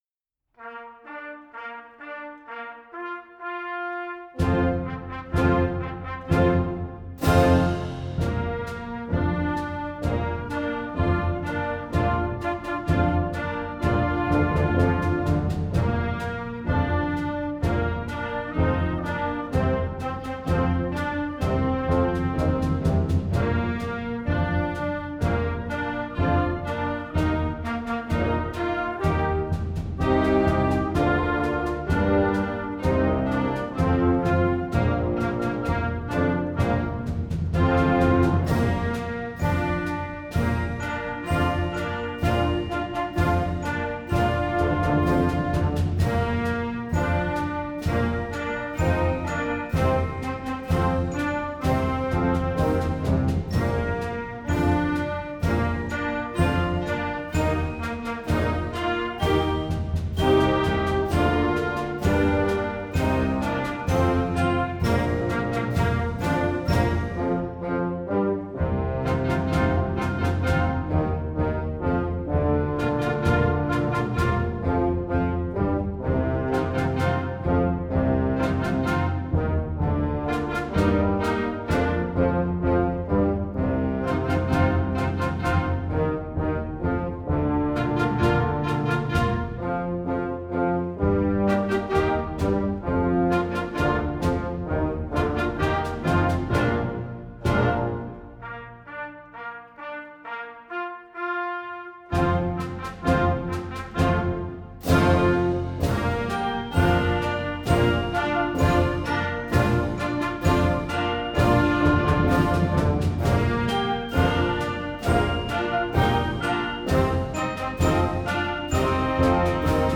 Voicing: Concert March